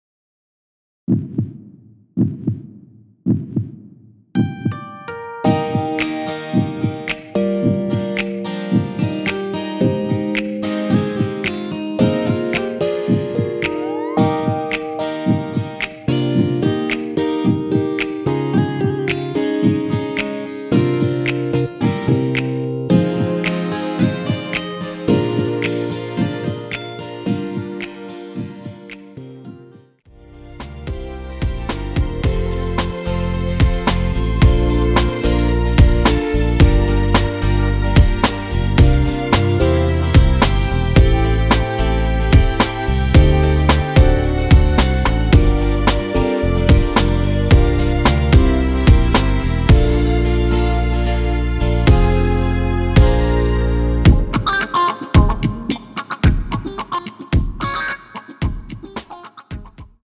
원키에서(+2)올린 MR입니다.
Eb
앞부분30초, 뒷부분30초씩 편집해서 올려 드리고 있습니다.